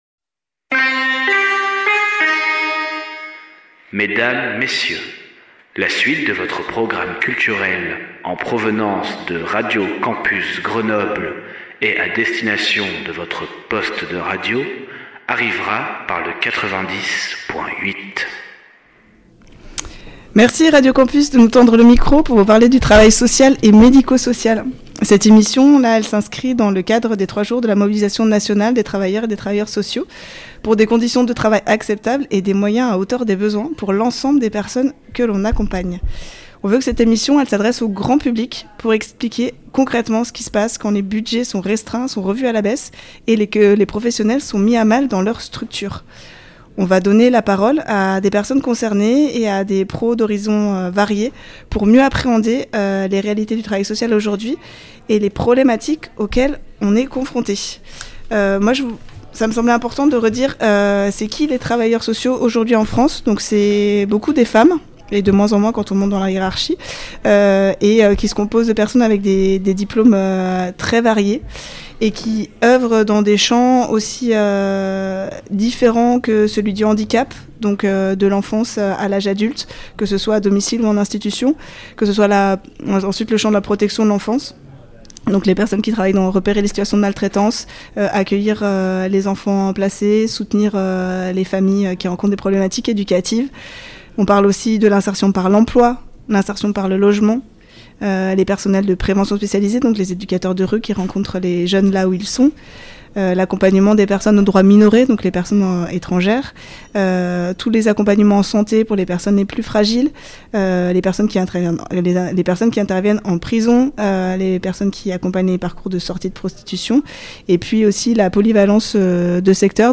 Durant l’émission nous avons donné la parole à des professionnels de divers horizons pour donner à entendre la réalité des métiers du social.
Émission enregistrée le 18/12/2026 en direct du bar Le Trankilou à Grenoble.